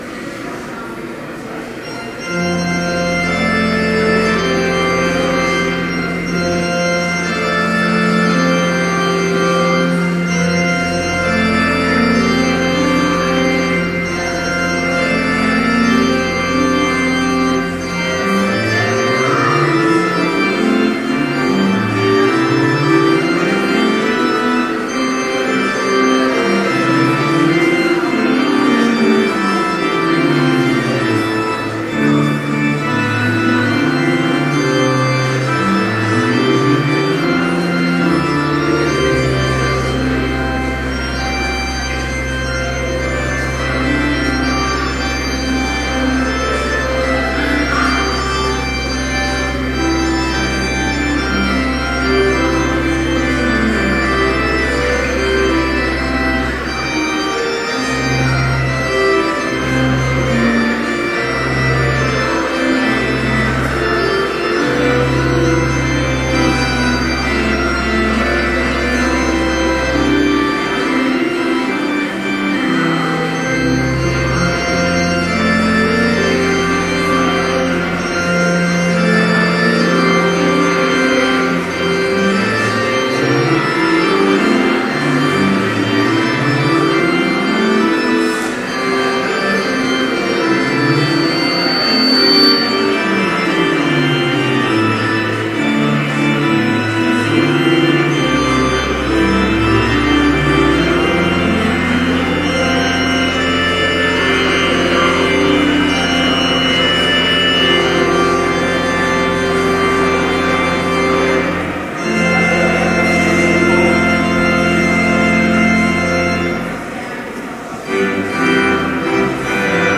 Complete service audio for Chapel - January 14, 2013